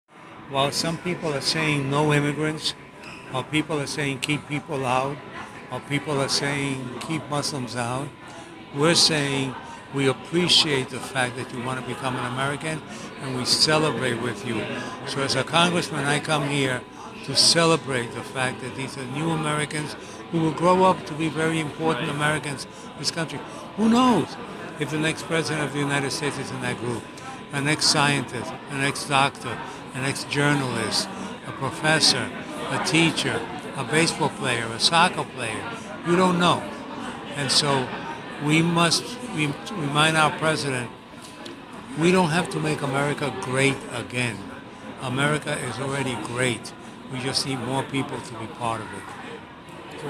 Interview with Congressman José E. Serrano, U.S. House of Representatives, 15 Congressional District of New York